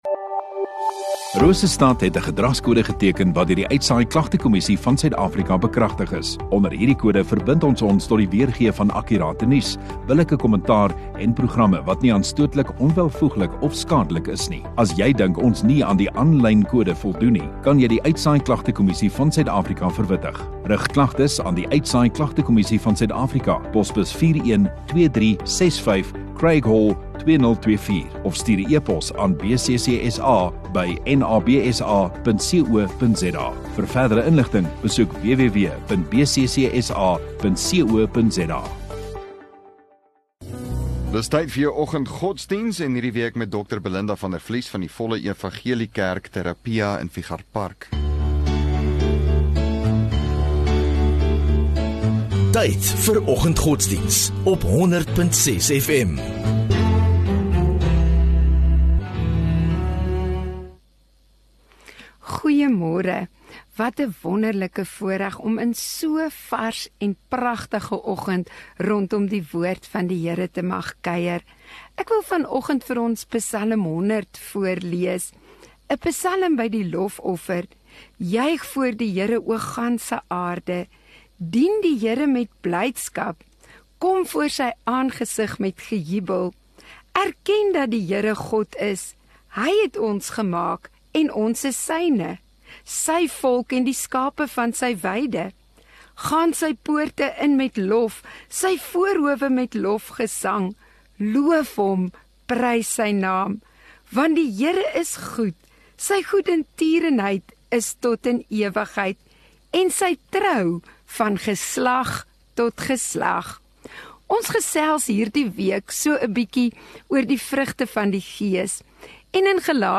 11 Mar Dinsdag Oggenddiens